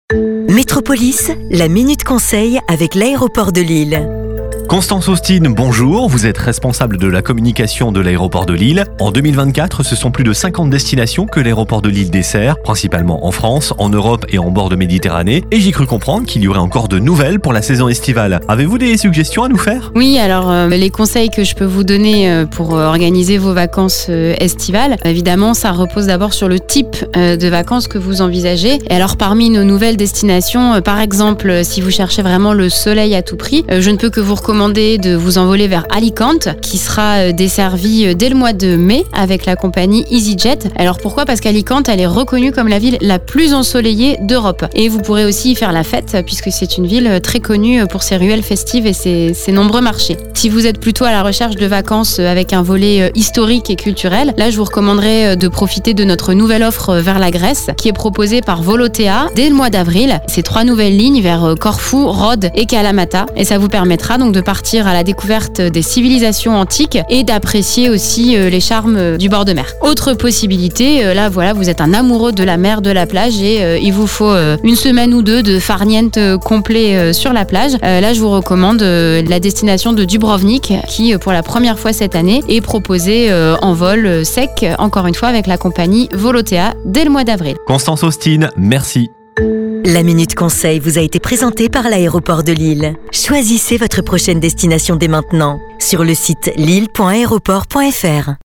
METROPOLYS Podcast Radio La minute Conseil METROPOLYS La minute Conseil 0:00 1 min 39 sec 12 mars 2024 - 1 min 39 sec LA MINUTE CONSEIL AVEC L'AEROPORT DE LILLE Découvrez toute l'actualité de l'Aéroport de Lille, ses nouvelles destinations, ses bons plans! Une interview